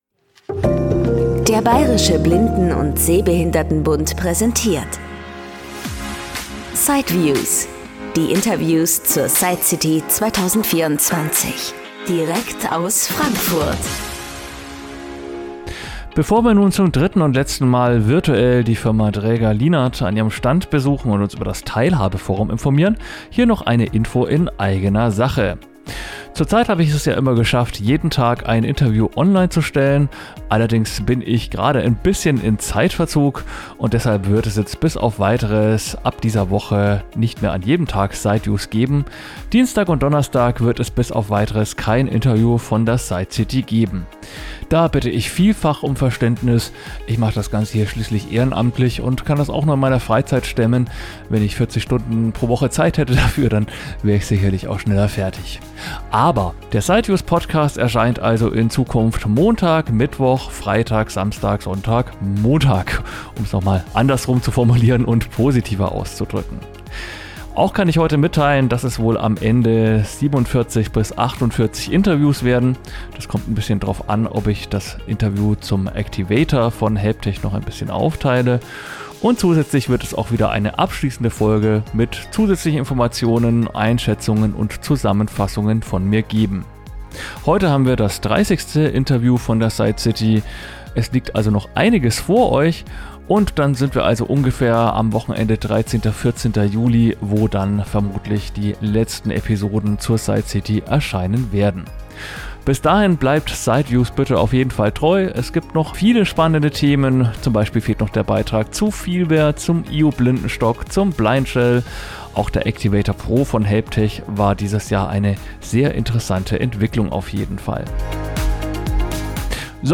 In Teil 3 des Interviews mit Dräger Lienert erfahren wir etwas über